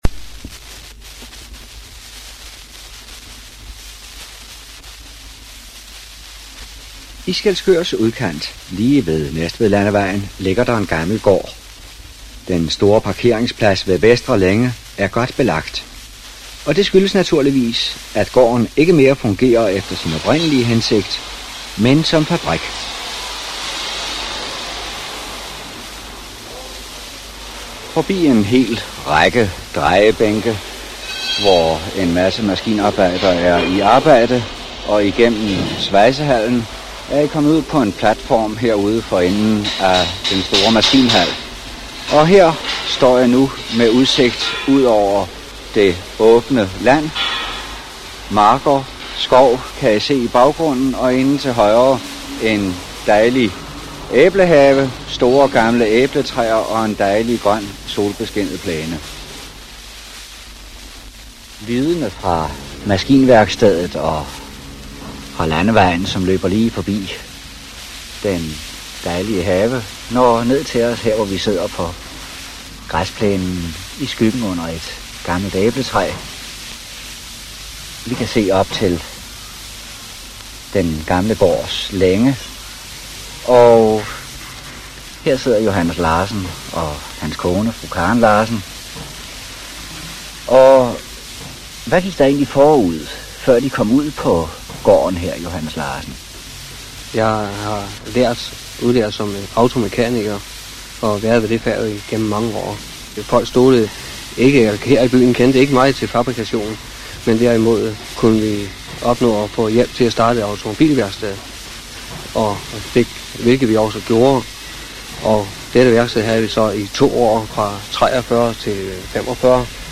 overspillet fra LP plade